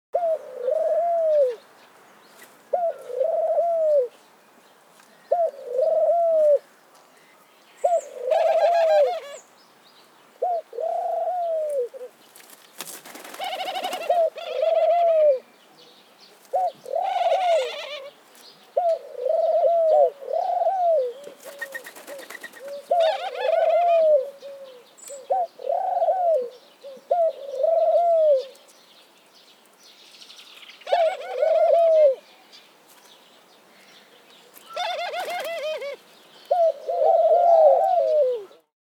دانلود آهنگ یاکریم 2 از افکت صوتی انسان و موجودات زنده
جلوه های صوتی
دانلود صدای یاکریم 2 از ساعد نیوز با لینک مستقیم و کیفیت بالا